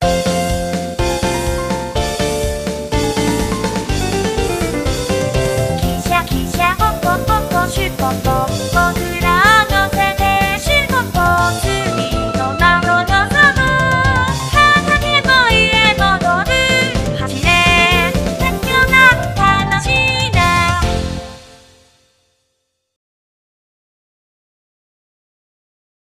サポセンジャー お花見コンサート。
ギター I
ベース
キーボード
ドラムス